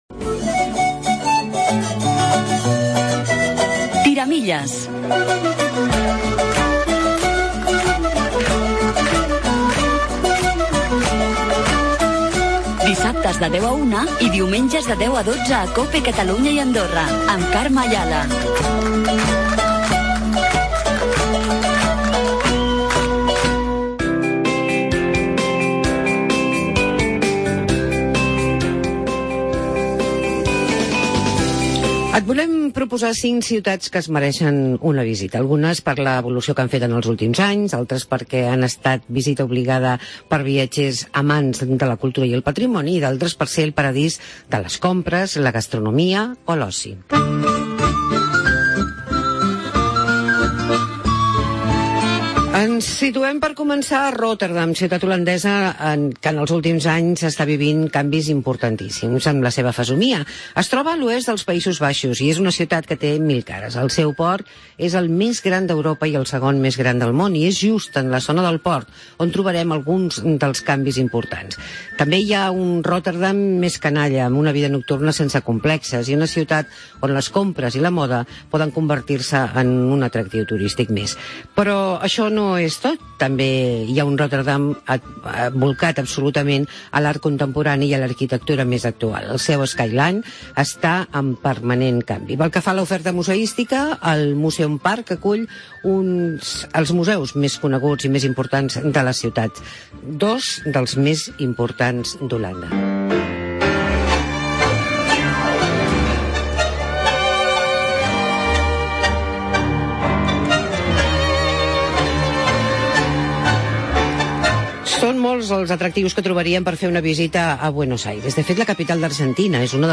El reportatge d'aquesta setmana ens porta per ciutats com Berlín, Marrakech, Estrasburg, Amsterdam